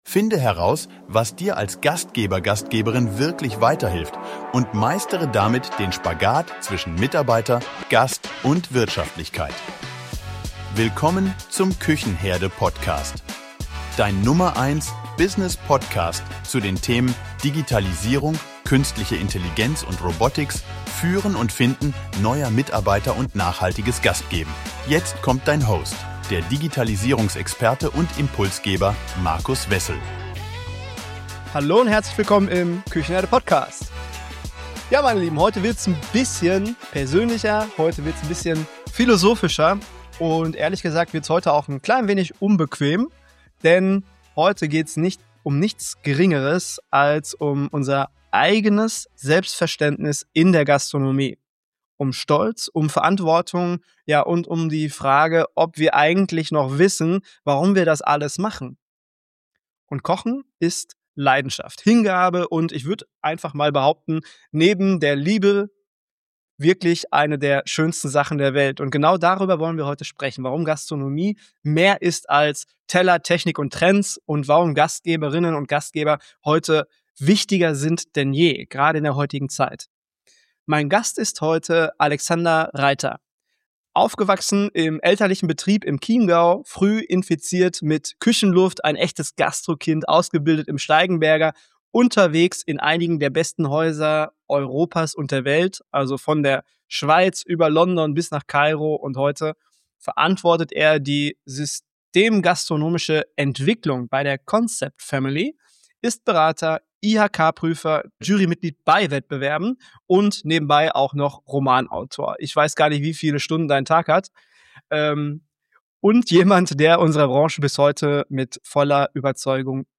Die Geschichte vom letzten Koch - Interview